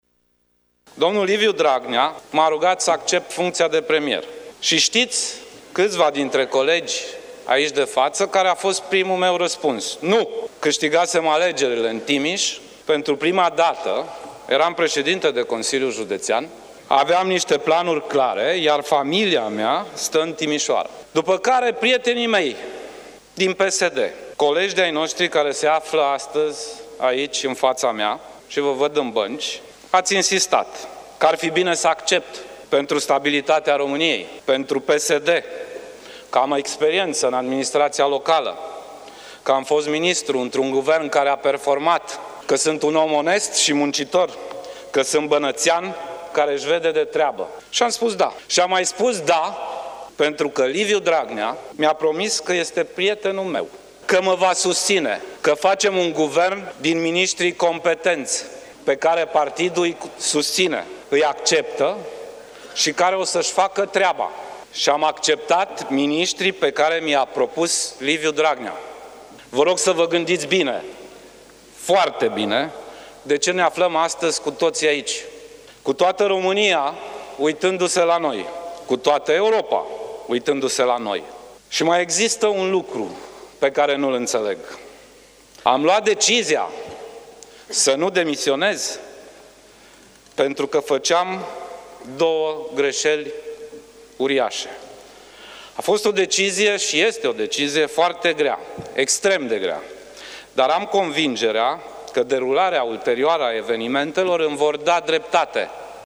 Sorin Grindeanu a prezentat apoi poziţia guvernului său faţă de moţiune, după care va urma dezbaterea în plen, fiecărui grup parlamentar fiindu-i atribuit un număr de minute stabilite, în funcţie de ponderea sa, iar la final premierul va avea din nou cuvântul, înainte de a se trece la vot: